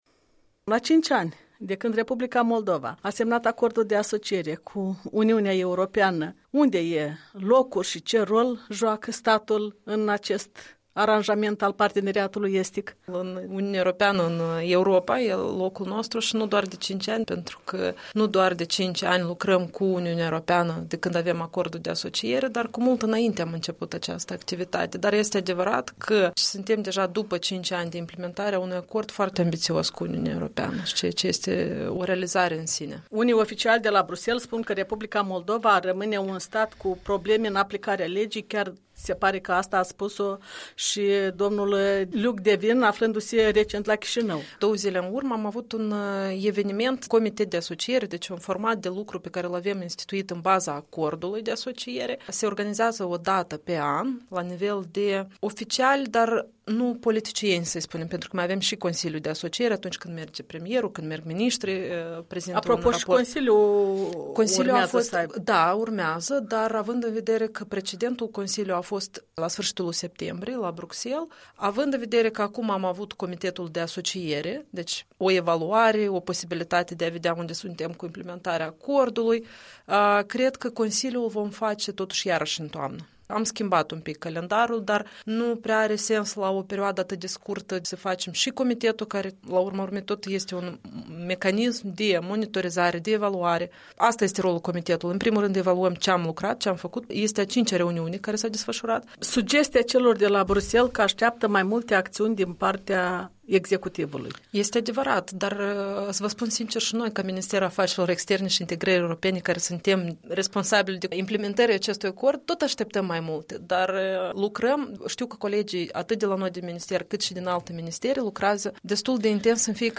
Interviu cu un secretar de stat de la Ministerul de Externe de la Chișinău.